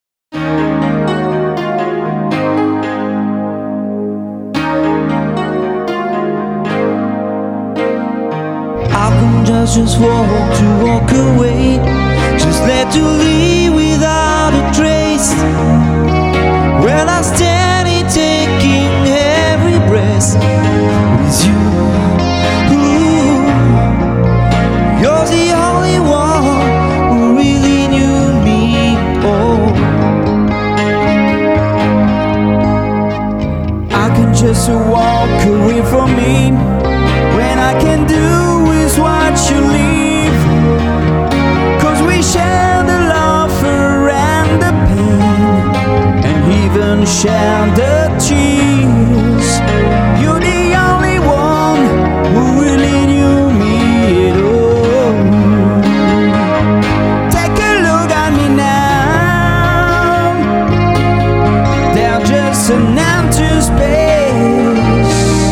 Studio Côtier, Frontignan, France.
Guitare
Chant, Choeurs
Basse